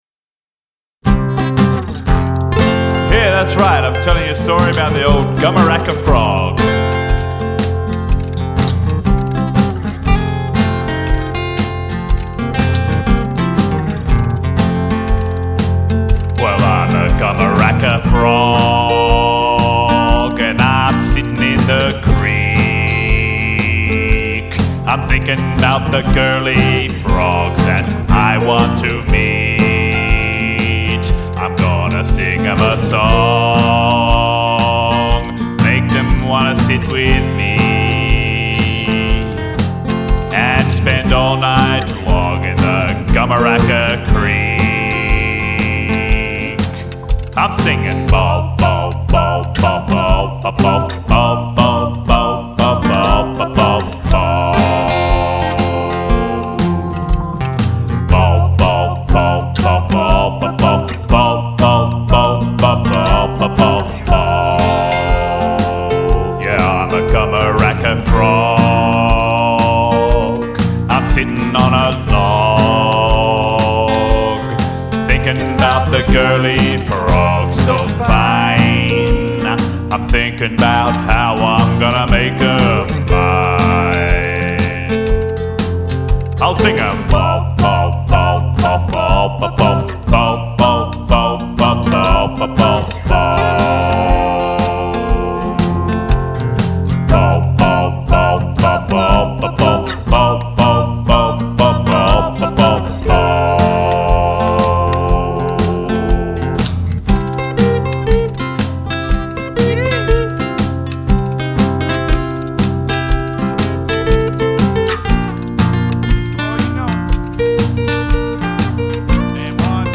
recorded in my home studio